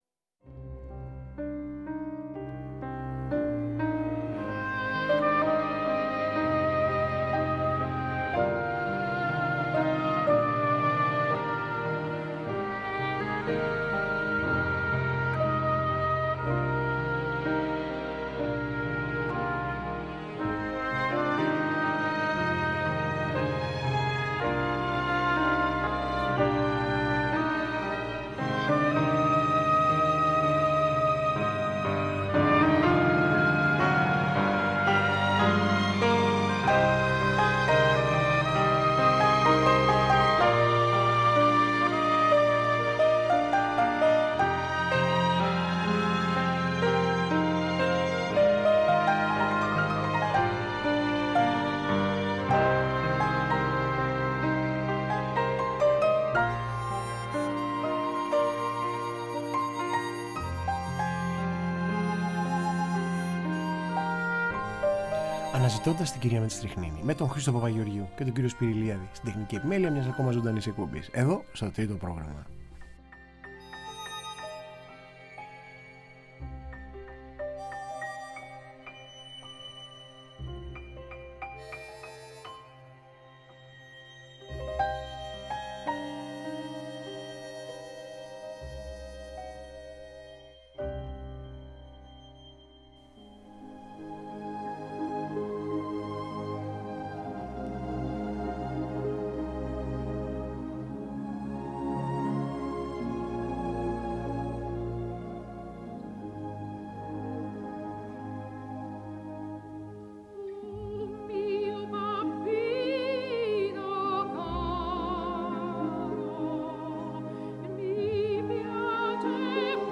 Στο στούντιο του Τρίτου Προγράμματος συντελεστές του ντοκιμαντέρ της Εθνικής Λυρικής Σκηνής που φέρνει στο φως μοναδικά ντοκουμέντα για την Κορυφαία Σοπράνο.